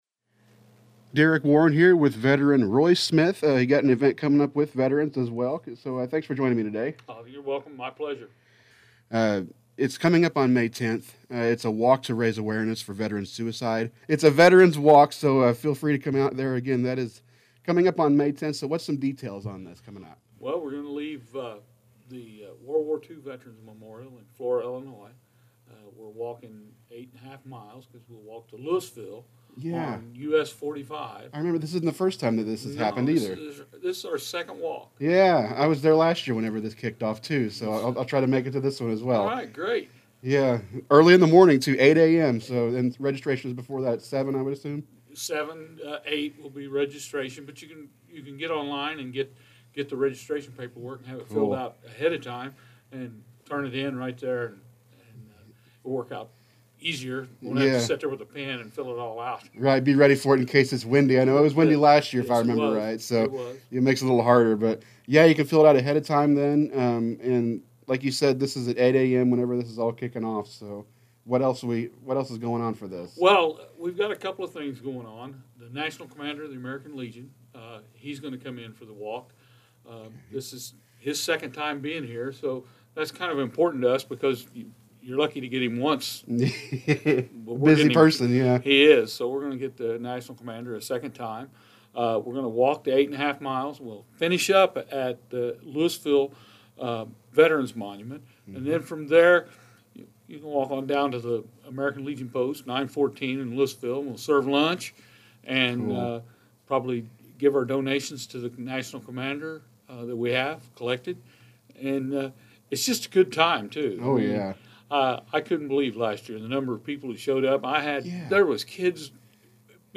be-the-one-veterans-walk-interview-4-28-25.mp3